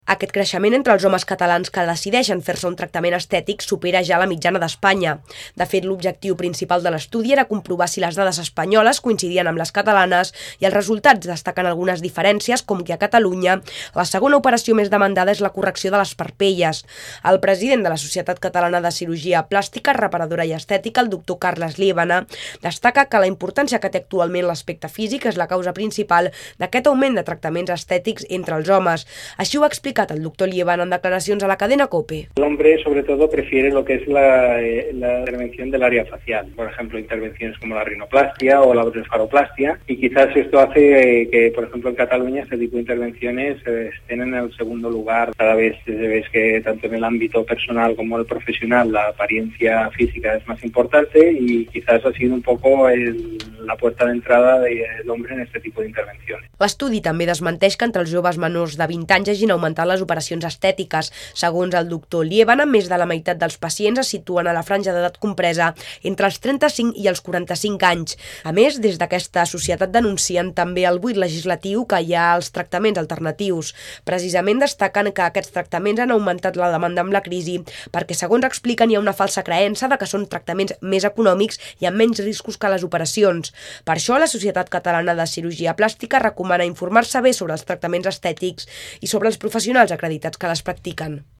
Informatiu migdia Cadena Cope (17 maig 2011)